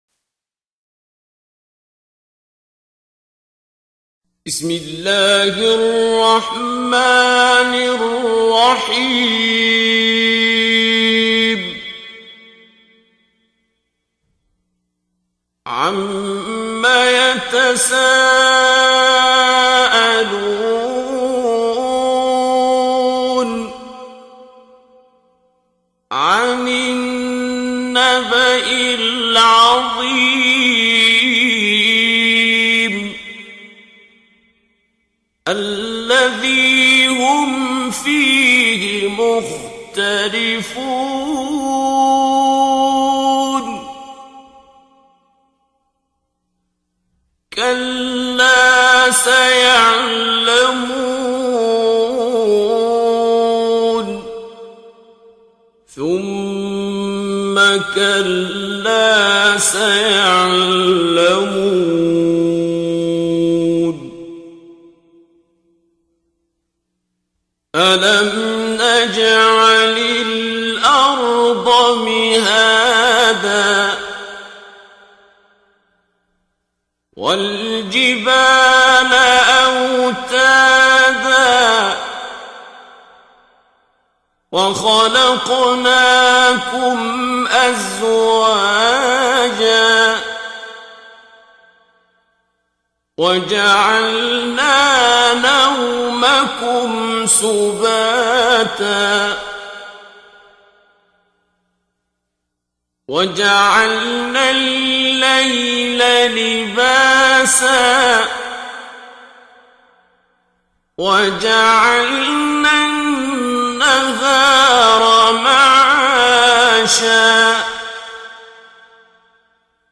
دانلود تلاوت زیبای سوره نباء آیات 1 الی 40 با صدای دلنشین شیخ عبدالباسط عبدالصمد
در این بخش از ضیاءالصالحین، تلاوت زیبای آیات 1 الی 40 سوره مبارکه نباء را با صدای دلنشین استاد شیخ عبدالباسط عبدالصمد به مدت 9 دقیقه با علاقه مندان به اشتراک می گذاریم.